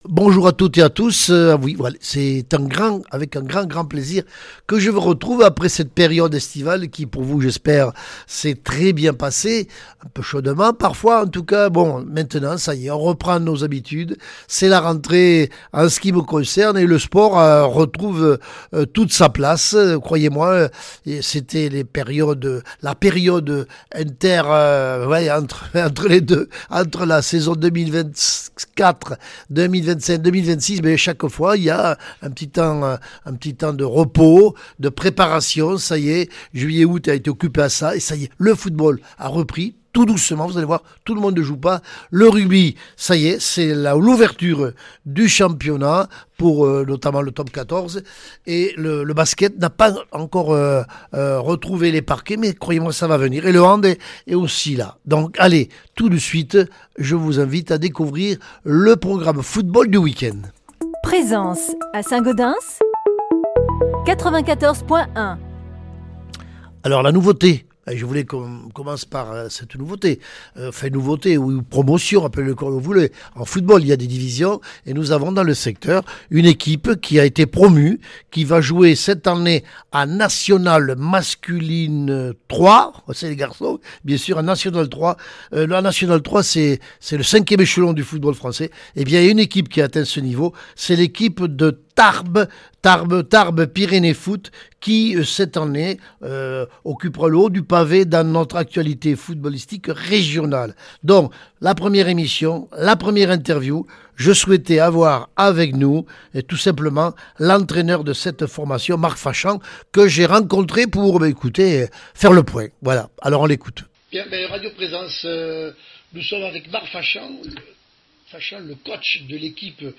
Interview et reportage du 05 sept.